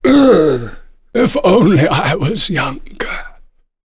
Ricardo-Vox_Dead.wav